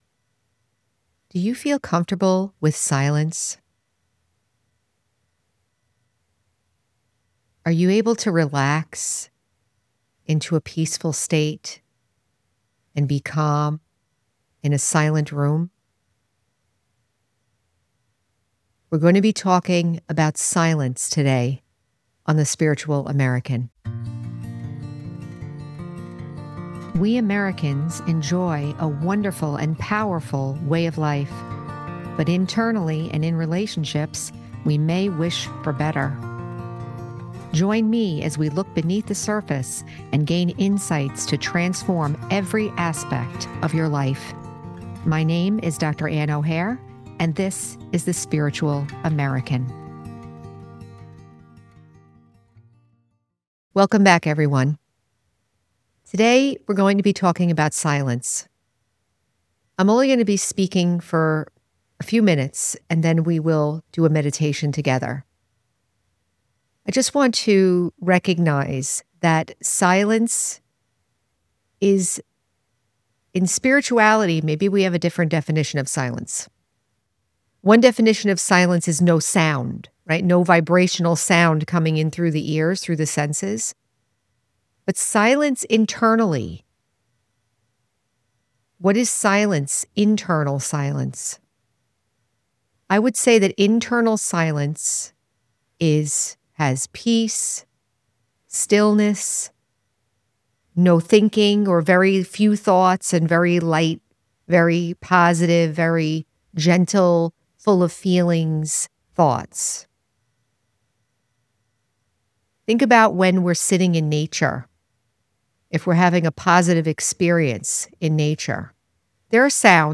Includes guided meditation.